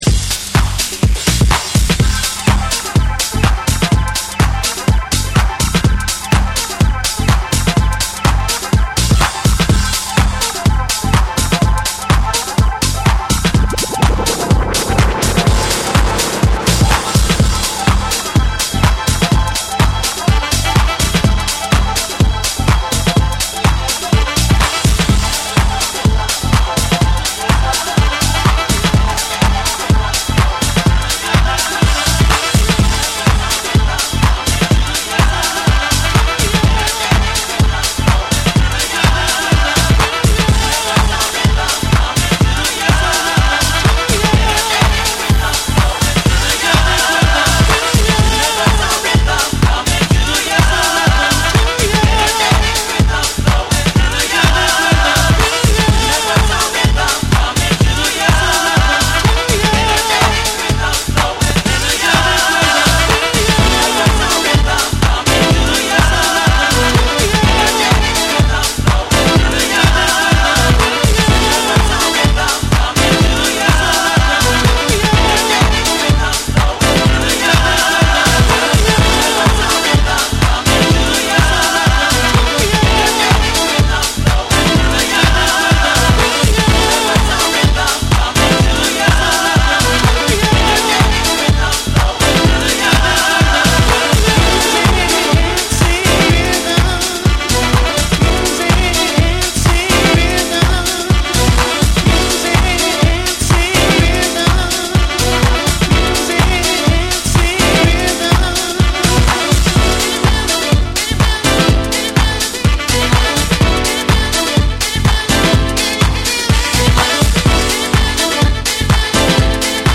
ソウルフルなヴォーカル・ハウス
躍動感あふれるビートに伸びやかな歌声が映える、90Sテイストの温かみある1曲。
TECHNO & HOUSE / DISCO DUB